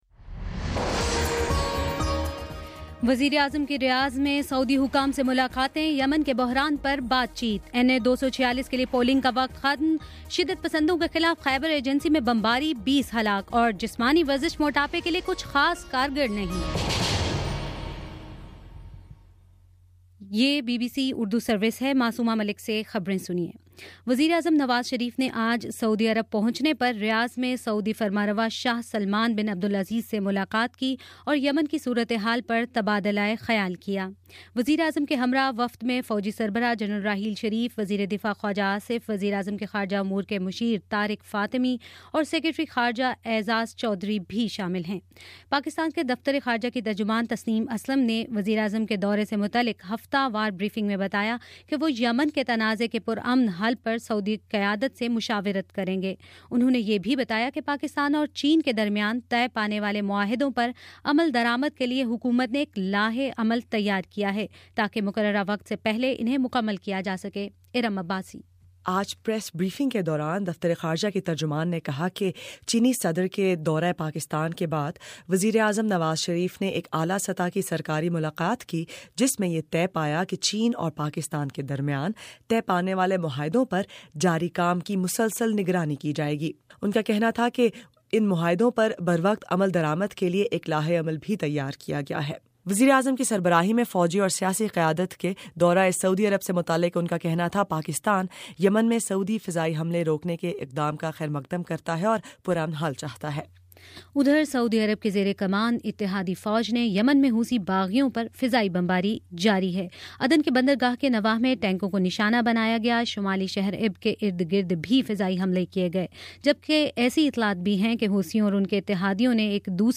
اپریل23: شام پانچ بجے کا نیوز بُلیٹن